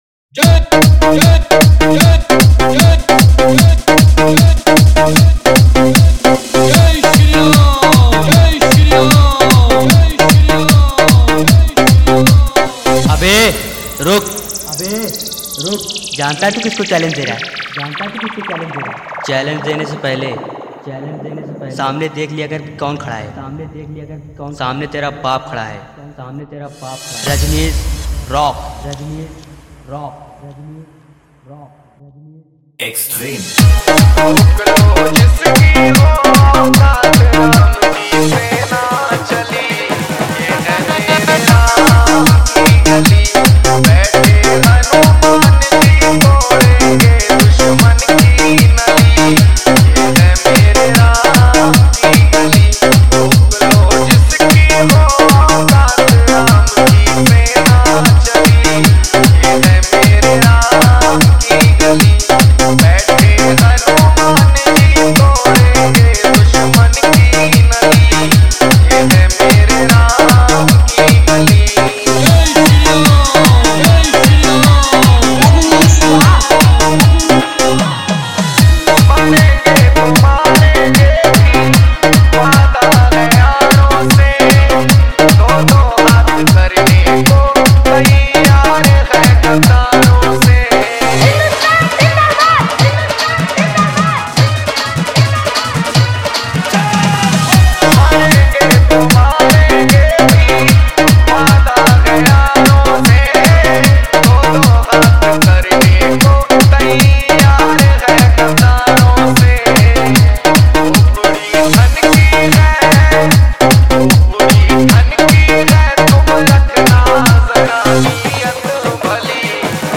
Ramnavami Dj Remix Songs